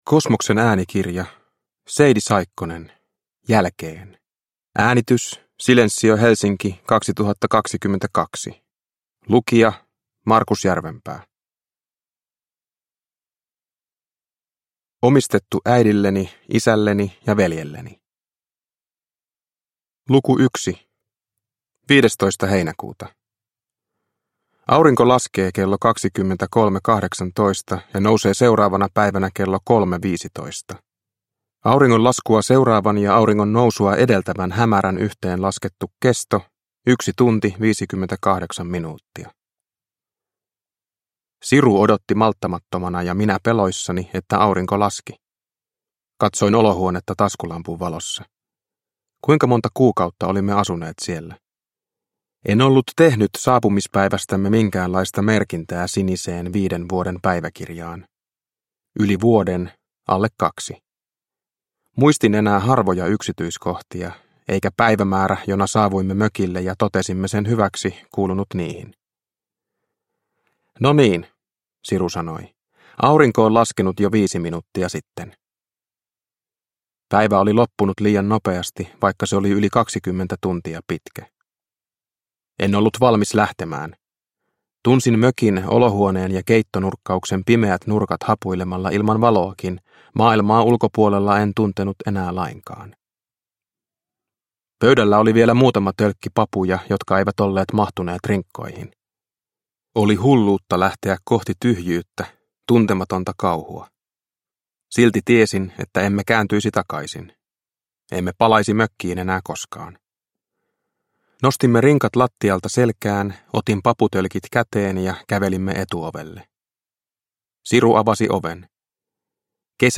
Jälkeen – Ljudbok – Laddas ner